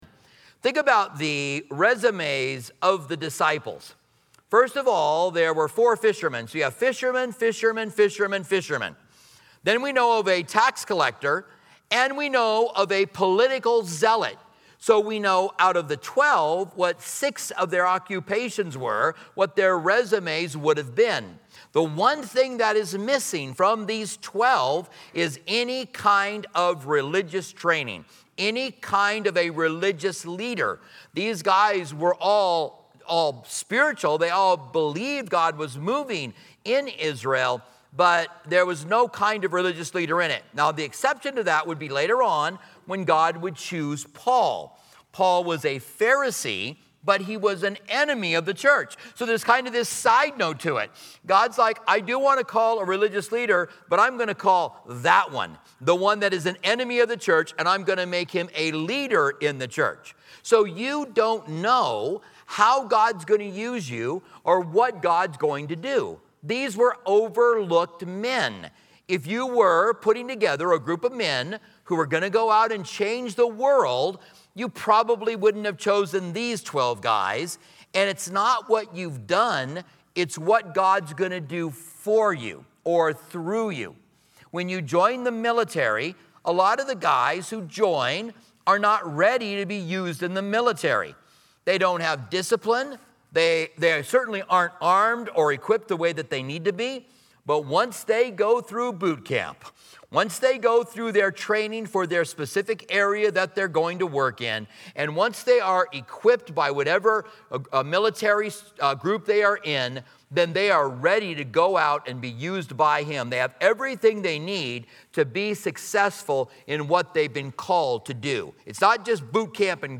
Join us at Calvary's Online Campus for an insightful Bible study that explores why Jesus chose the twelve disciples from ordinary backgrounds like fishermen and tax collectors, instead of the educated elite. Discover the significance of prayer in His decision-making process and meet each disciple, learning about their unique stories and transformations.